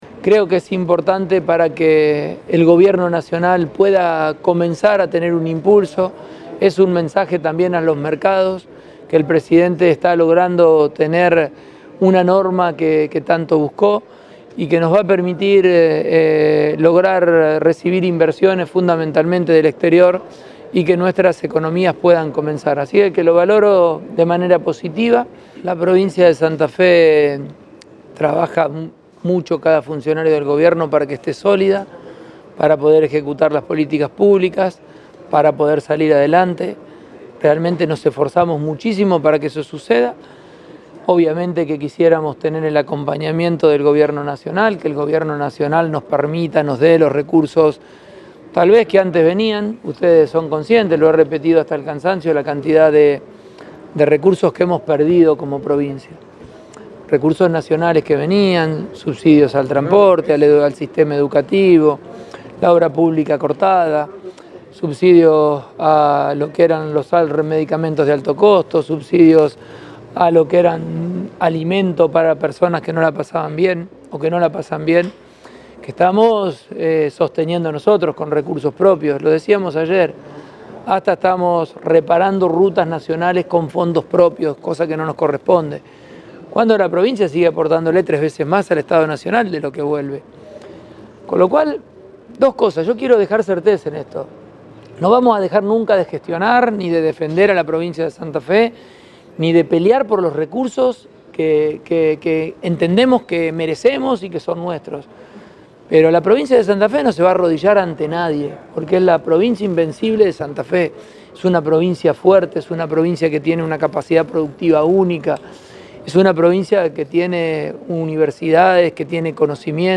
Las declaraciones del gobernador Pullaro